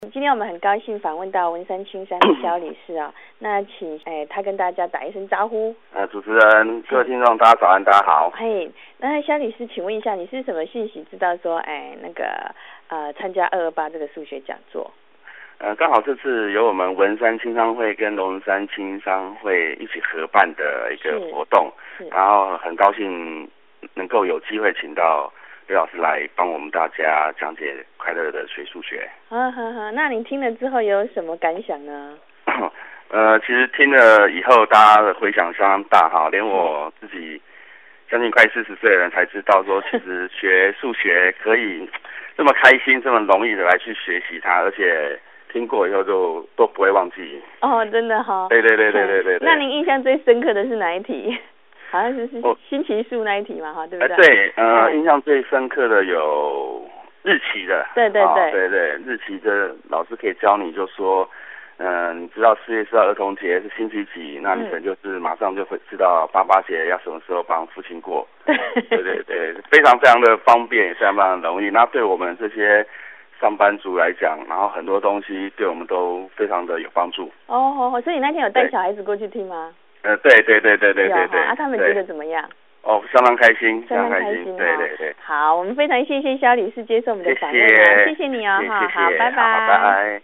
聲音)：９８年２月２８日台北市龍山青商會數學講座。